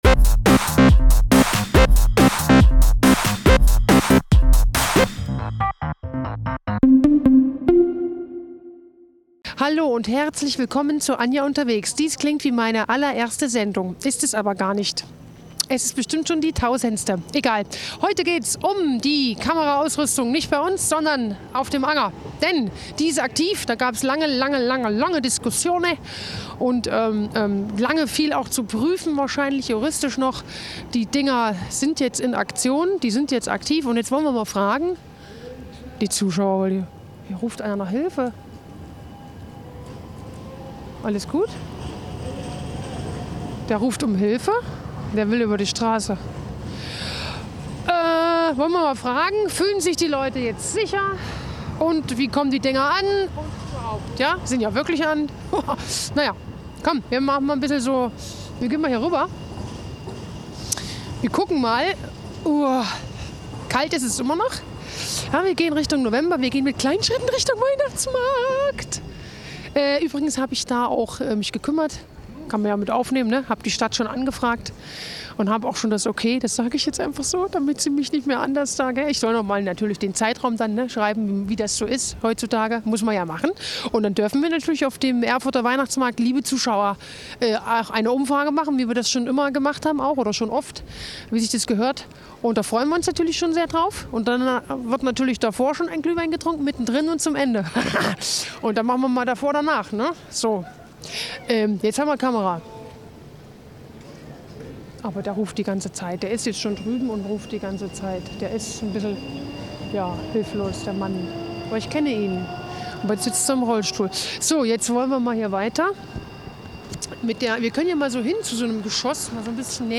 Die Debatte gipfelte in einem von der AfD-Fraktion beantragten Sonderplenum des Th�ringer Landtages am 16. Januar 2026.